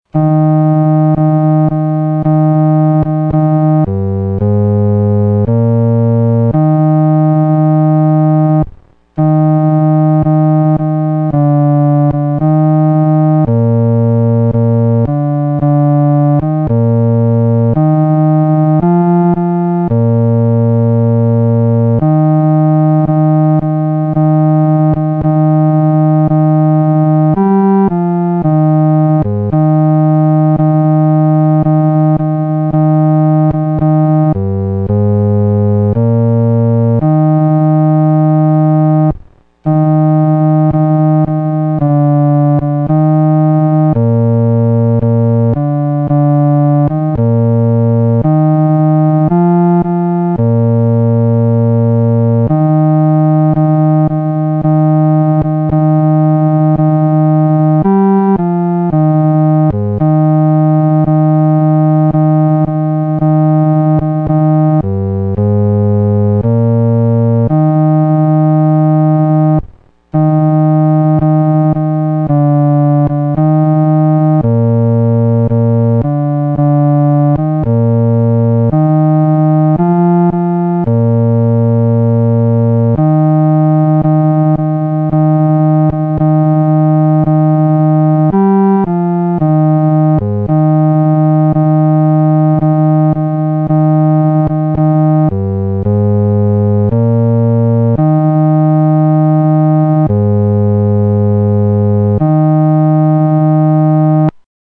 独奏（第四声）